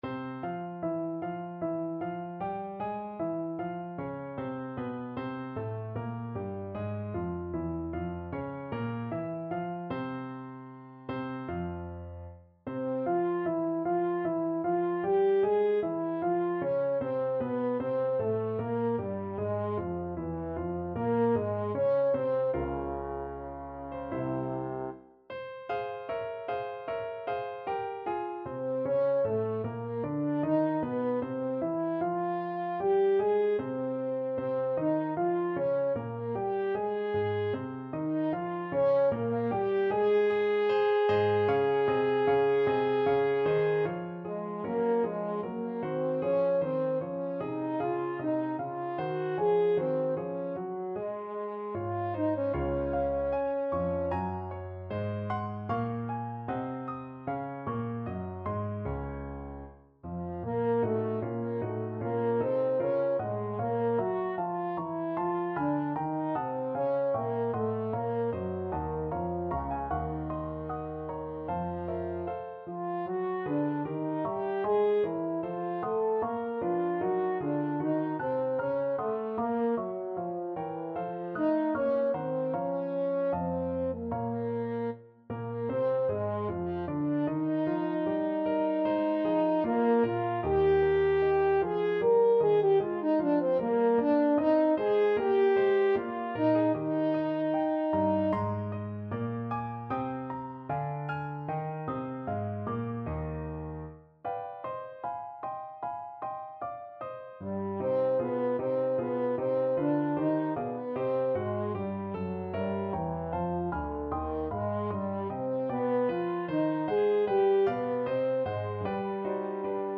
French Horn
F minor (Sounding Pitch) C minor (French Horn in F) (View more F minor Music for French Horn )
4/4 (View more 4/4 Music)
Larghetto (=76)
Classical (View more Classical French Horn Music)